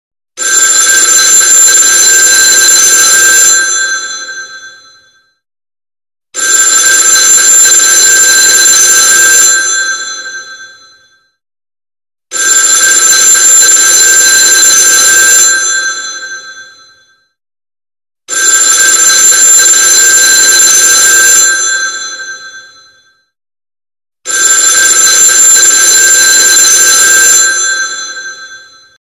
Звонок старого телефона